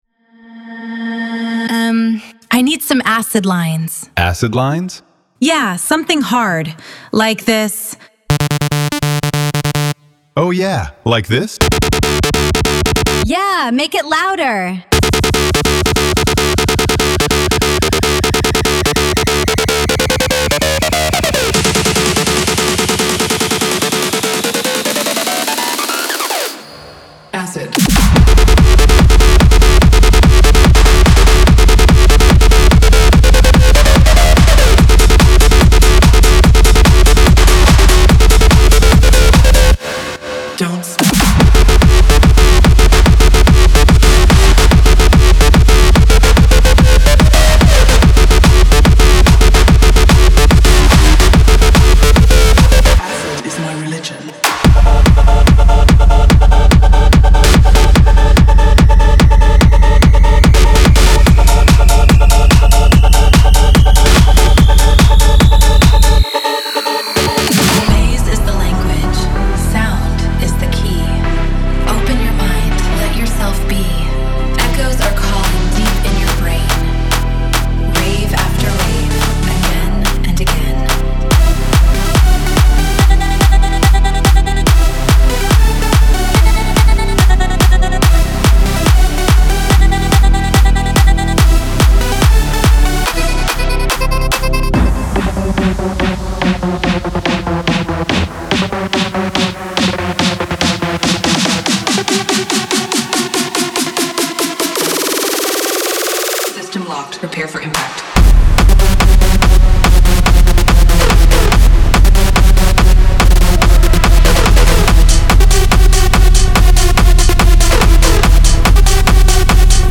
Genre:Techno
注意：オーディオデモはラウドで圧縮され均一に聴こえるように処理されています。
includes: drums, bass, synths, fx, ai vocals
143 - 160 BPM